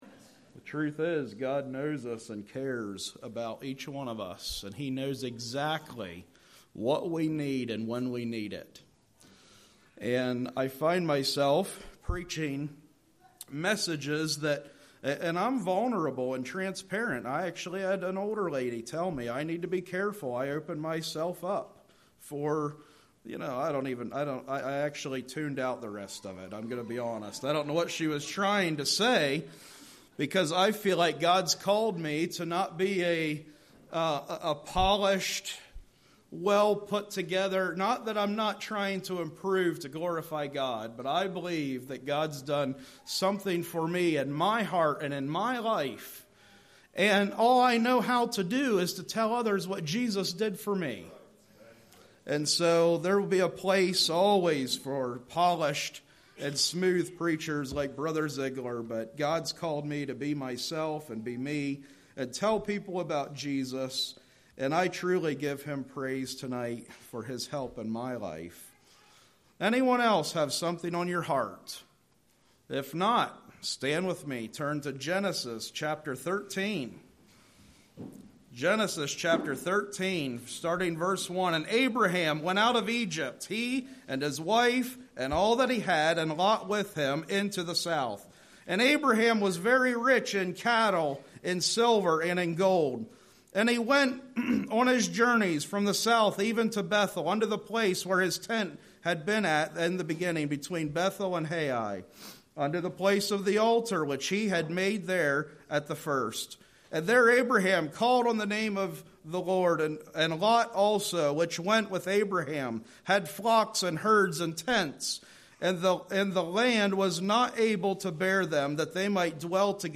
Spring Revival 2026